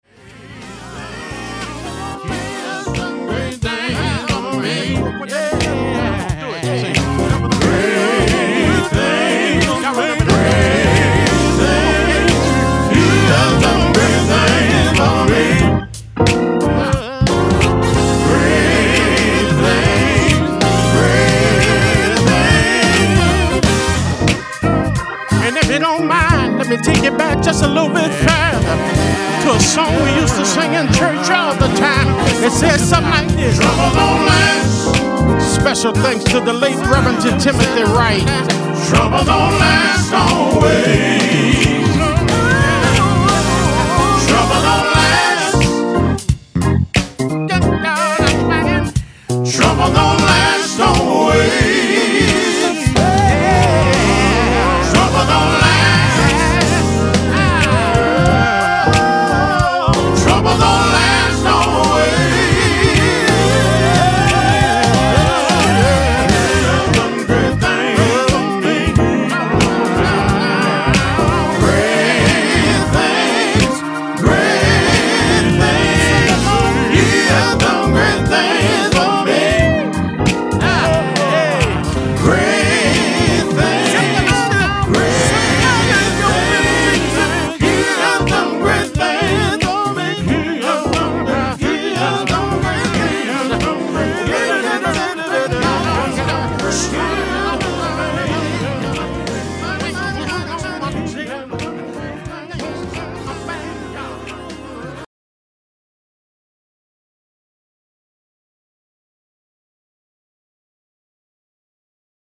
INSPIRATIONAL